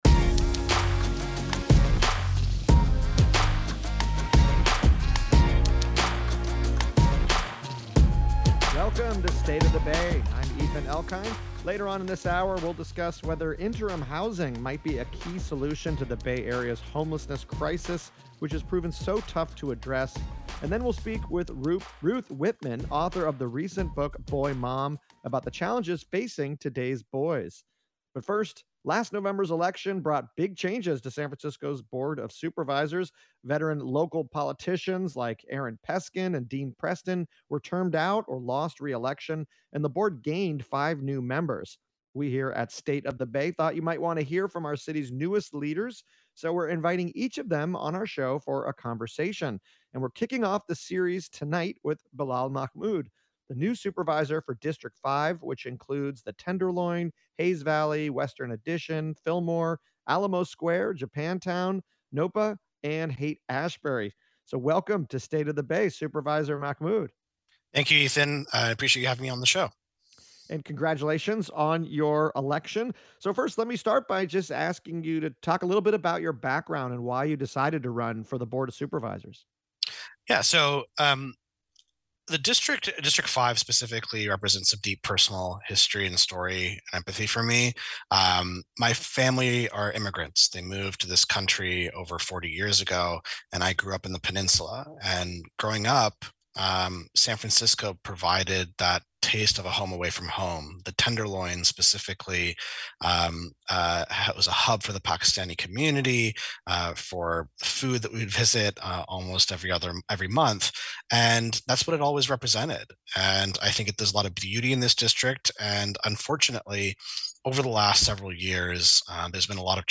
Lurie's First 100 Days: A Media Roundtable